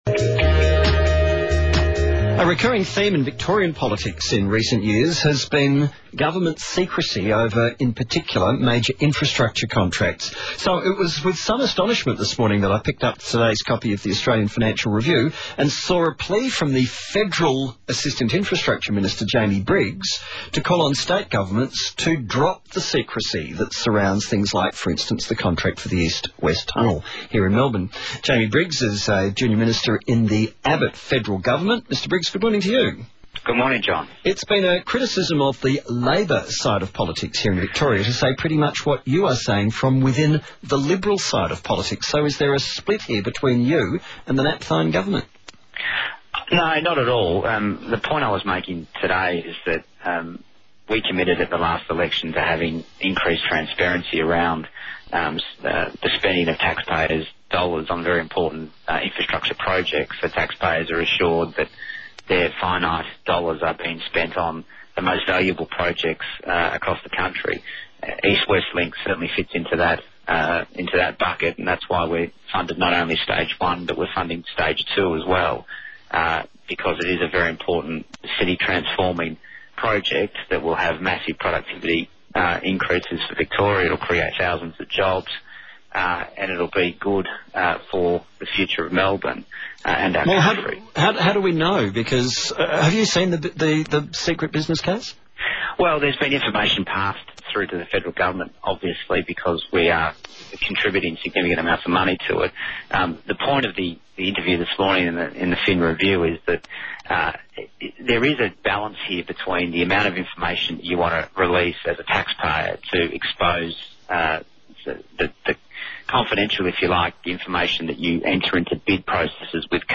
Melbourne's East West Link - Interview with 774 ABC Melbourne Radio - Friday, 7th November 2014